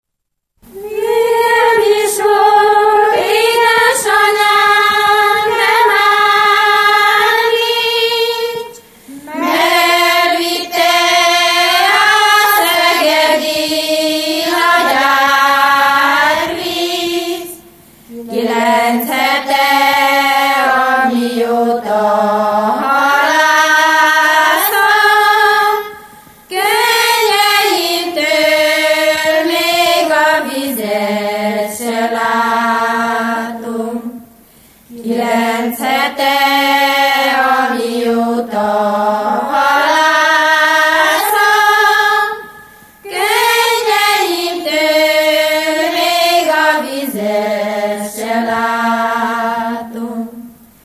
Erdély - Kolozs vm. - Kispetri
Stílus: 6. Duda-kanász mulattató stílus
Kadencia: 8 (5) 7 1